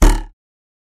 На этой странице представлены звуки подзатыльника – резкие, неожиданные аудиоэффекты.
Подзатыльник - Юмористическая версия